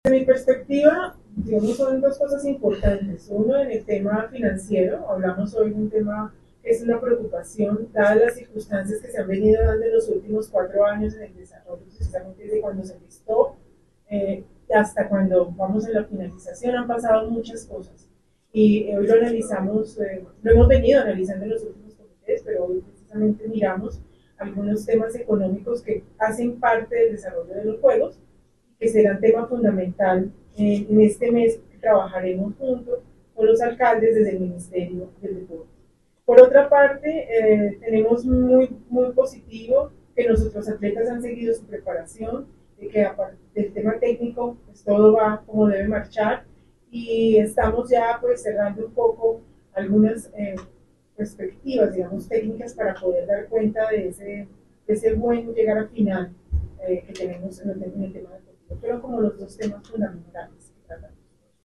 FULL_MINISTRA_DEL_DEPORTE.mp3